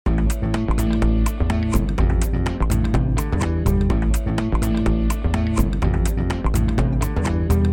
Background
Music Background